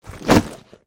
Звуки сумки, ранца
Звук: сумку кинули на землю